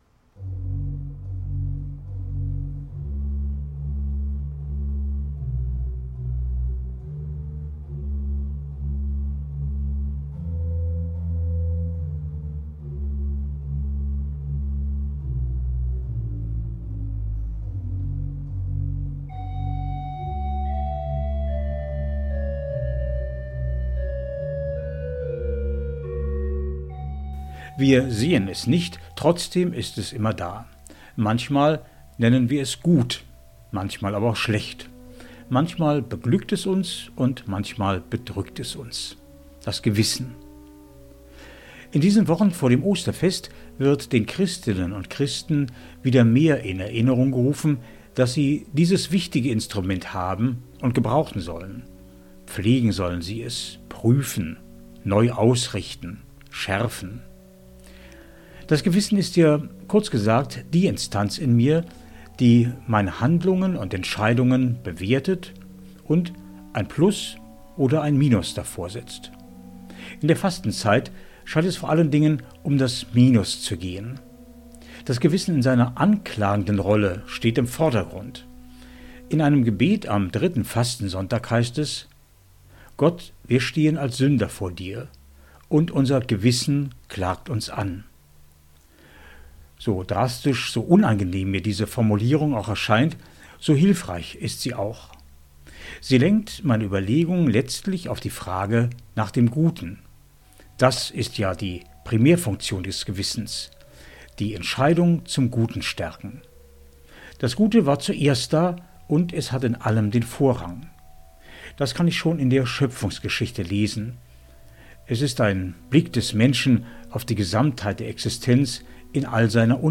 Eine Meditation zur Fastenzeit
MEDITATION